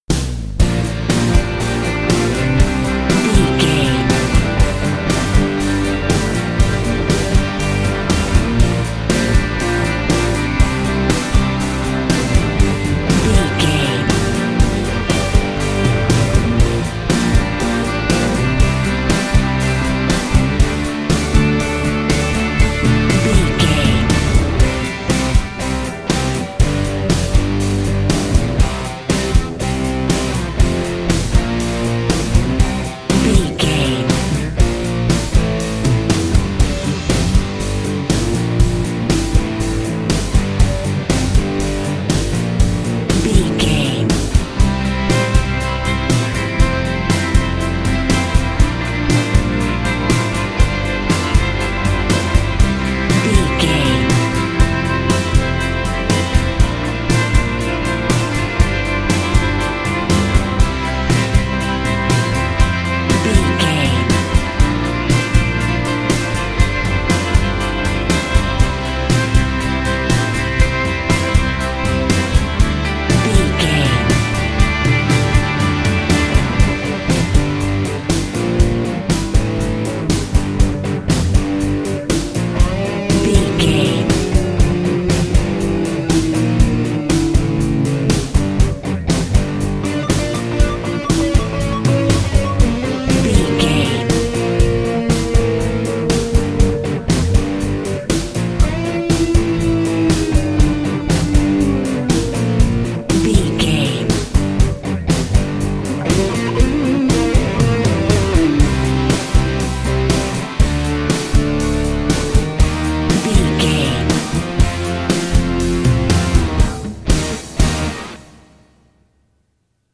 Aeolian/Minor
drums
electric guitar
Sports Rock
pop rock
hard rock
metal
lead guitar
bass
aggressive
energetic
intense
powerful
nu metal
alternative metal